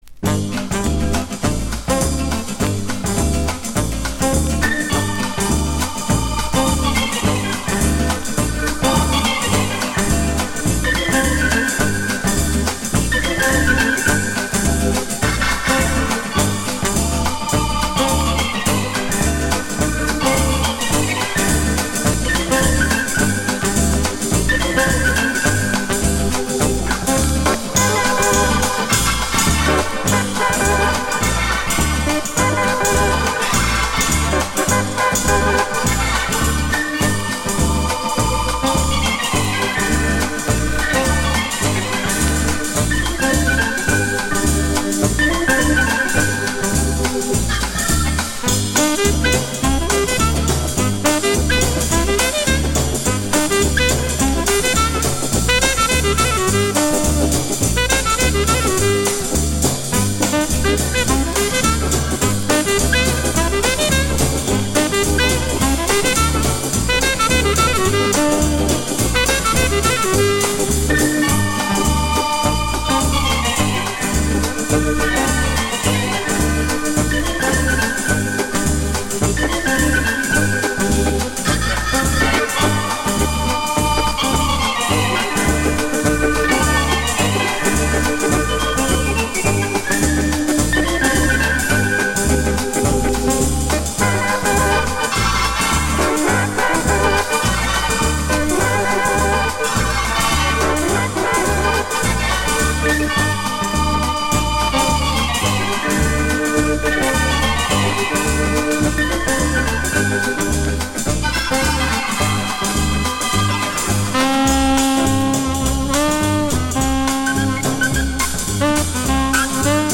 инструментальное произведение